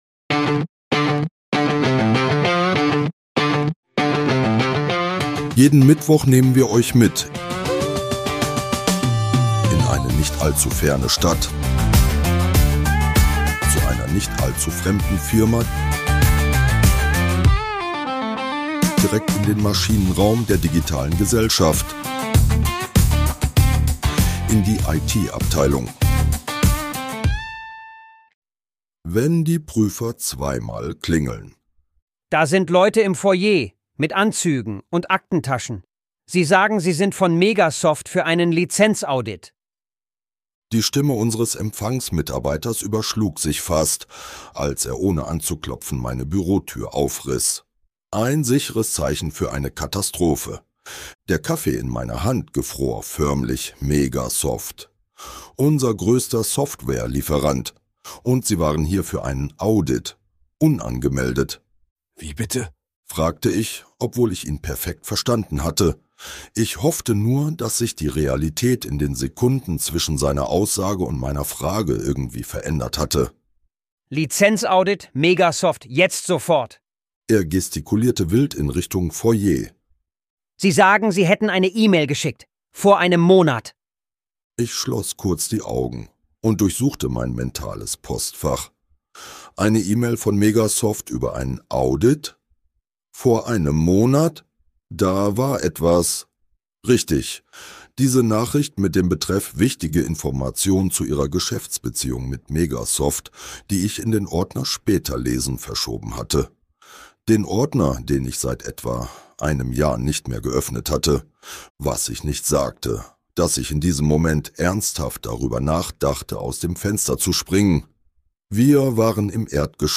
Dieser Podcast ist Comedy.
(AI generiert) Mehr